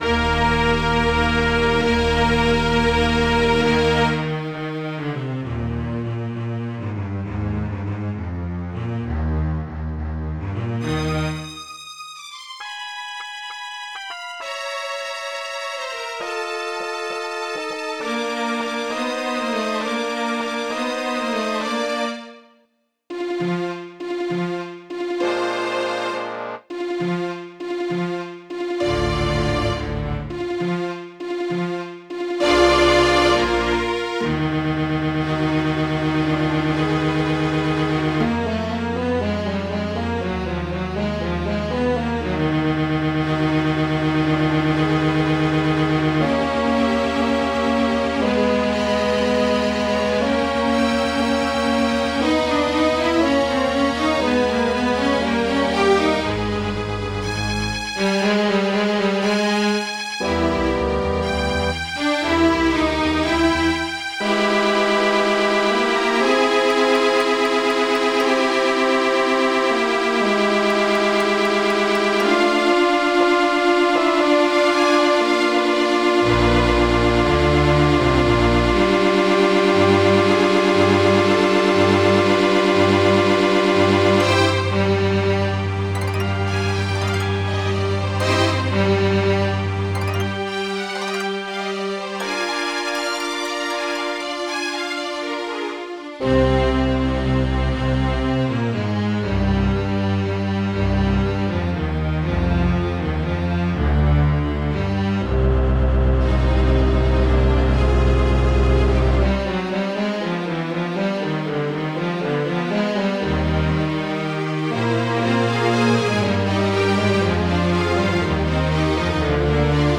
Macbeth Overture - Orchestral and Large Ensemble - Young Composers Music Forum
Well, I know the sound quality is sad enough to cry :'v but what do u think?